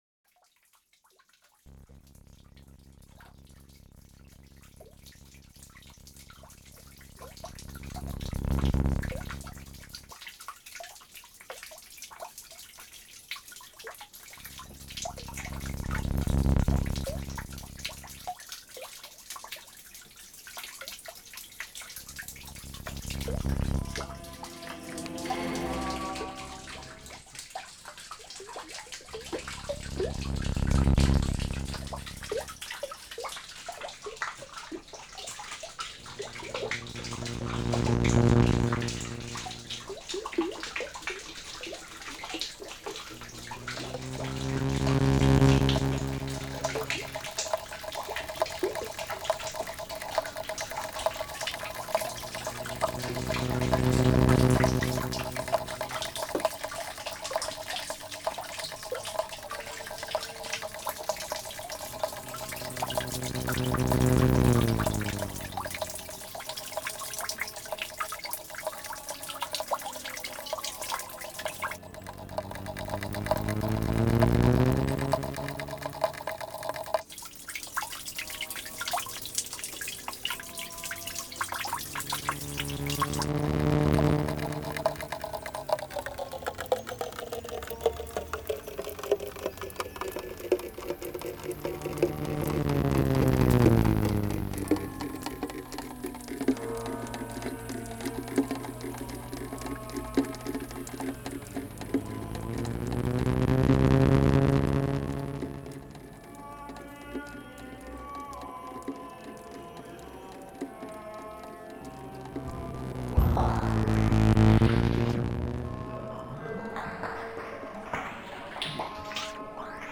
The sound material consists of the acoustic and electromagnetic sounds of the city where the project is presented.
Examples Electrical Jubilee, Rome 2025
for Midi controller, Apple computer, acoustic and electromagnetic sounds